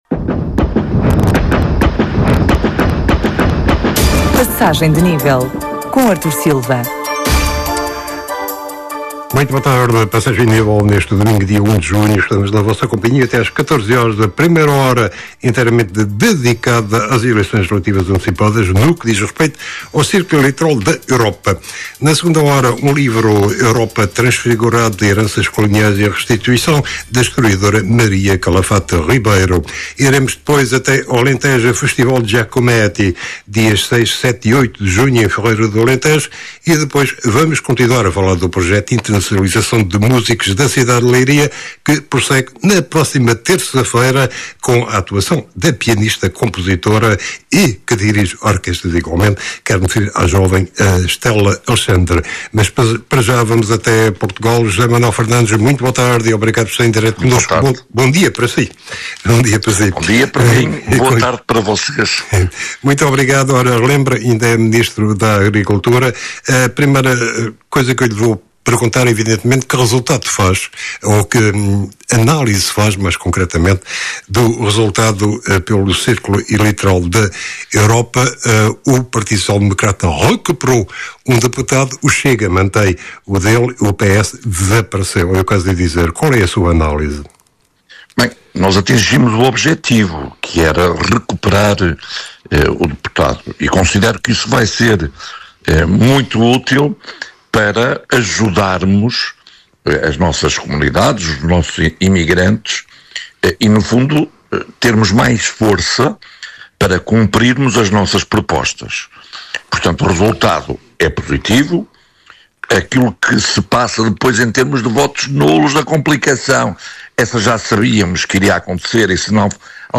Passagem de Nível, magazine de informação na Rádio Alfa